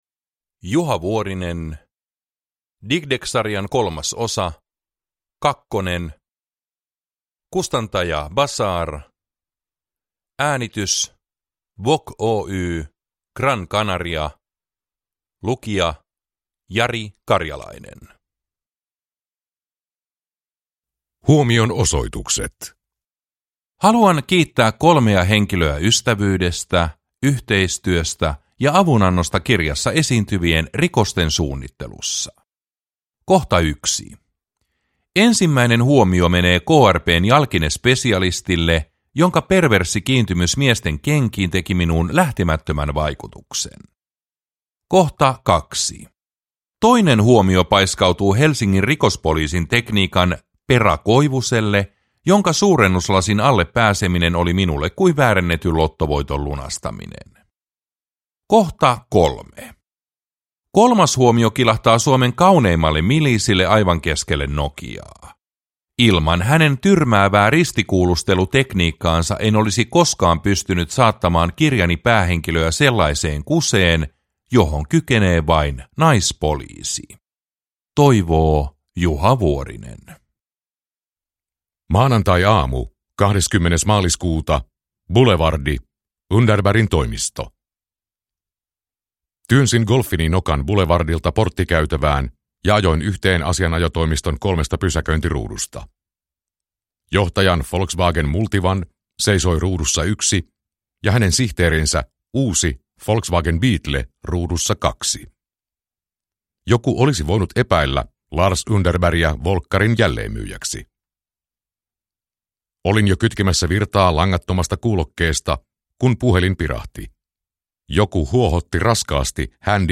Kakkonen – Ljudbok